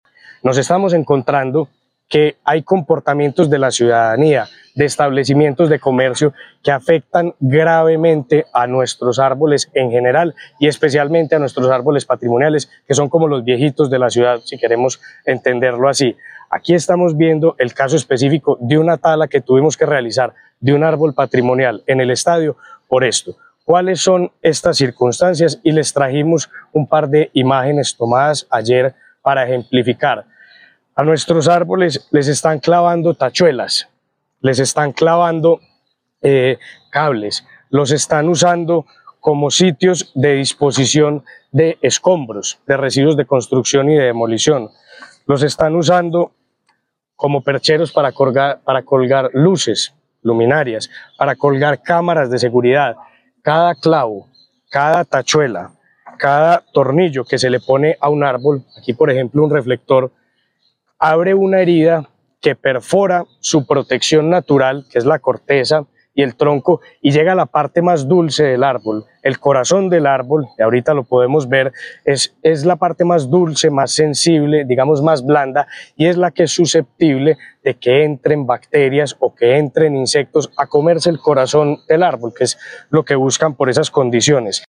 Declaraciones-subsecretario-de-Recursos-Naturales-Esteban-Jaramillo-Ruiz.mp3